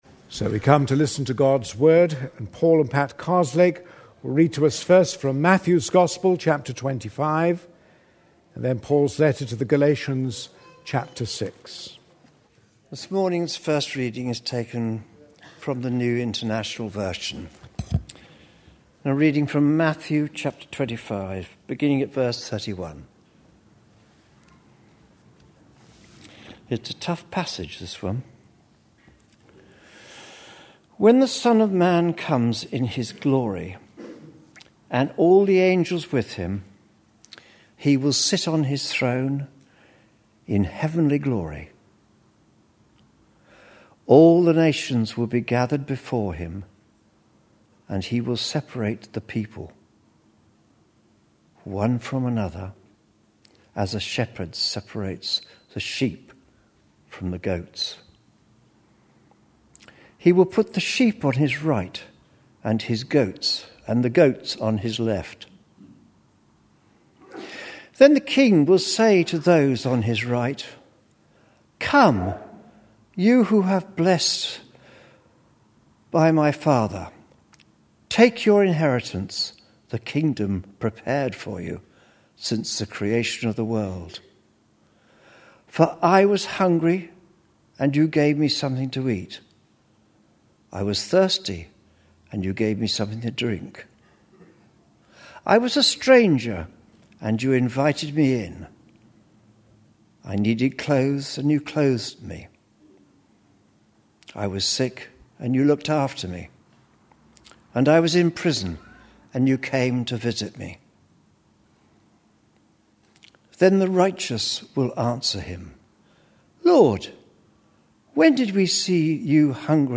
A sermon preached on 12th December, 2010, as part of our Parables of Matthew series.